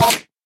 Minecraft Version Minecraft Version snapshot Latest Release | Latest Snapshot snapshot / assets / minecraft / sounds / mob / endermen / hit3.ogg Compare With Compare With Latest Release | Latest Snapshot